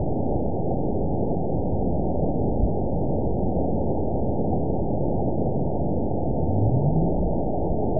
event 920836 date 04/11/24 time 02:35:44 GMT (1 year, 1 month ago) score 9.51 location TSS-AB02 detected by nrw target species NRW annotations +NRW Spectrogram: Frequency (kHz) vs. Time (s) audio not available .wav